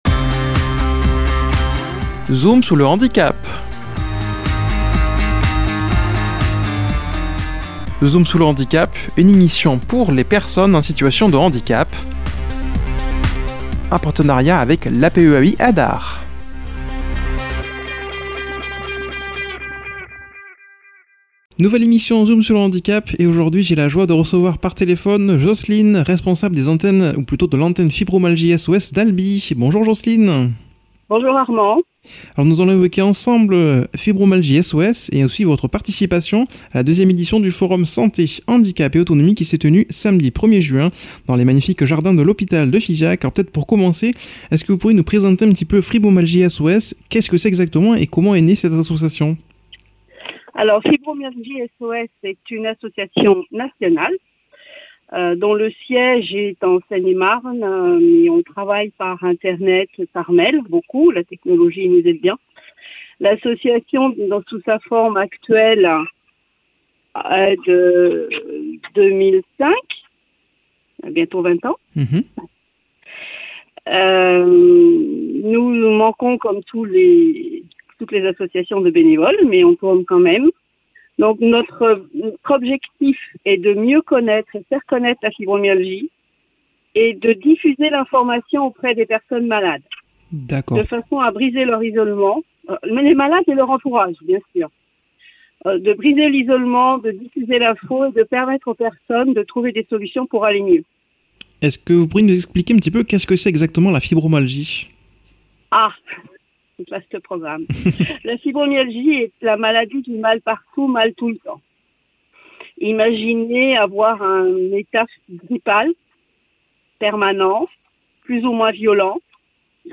a comme invitée par téléphone